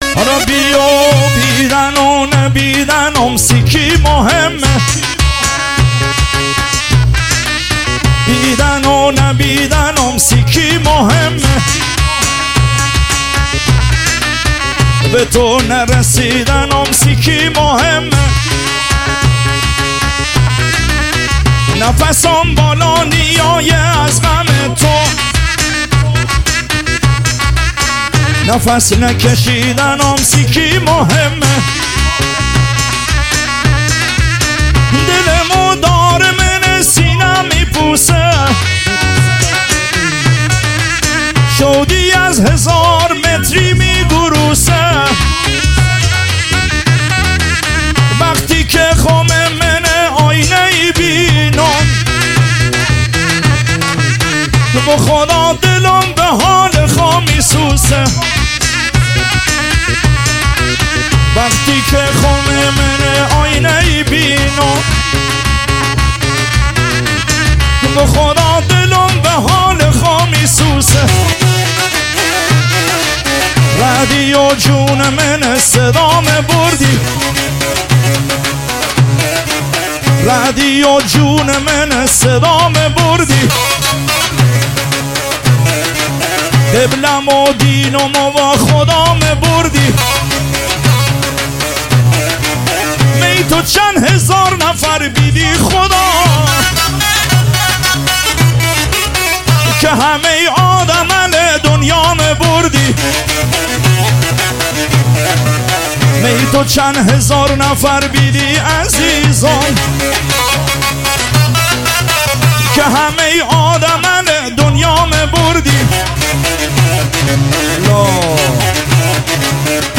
عاشقانه غمگین محلی لری